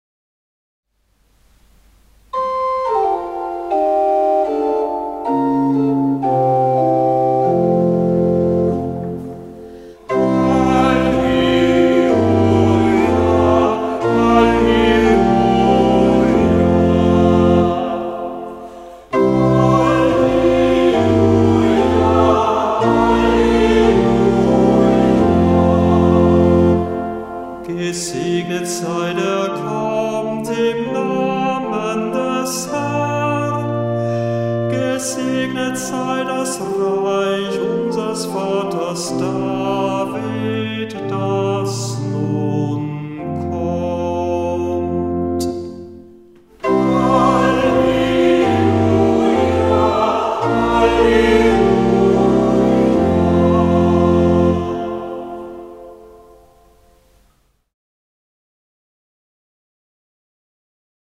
Ruf vor dem Evangelium - November 2025
Hörbeispiele aus dem Halleluja-Büchlein
Kantor wenn nicht anders angegeben